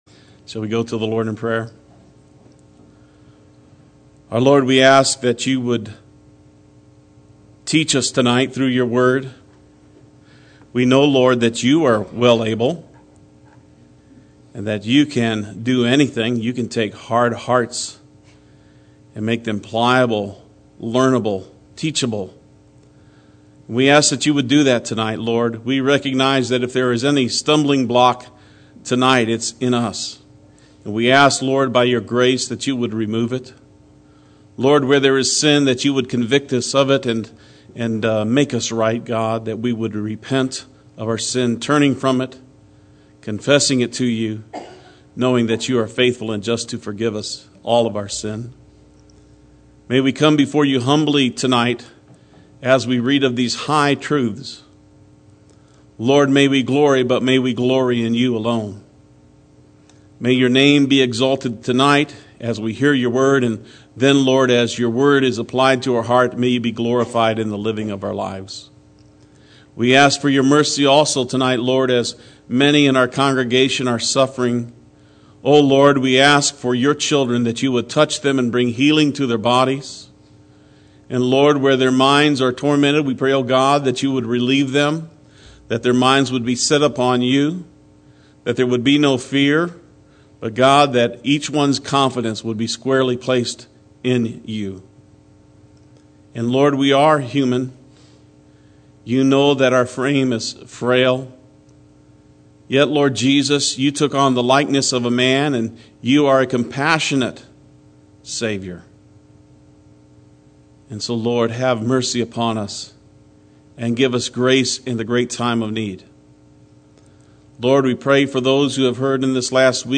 Play Sermon Get HCF Teaching Automatically.
Psalm 93 Wednesday Worship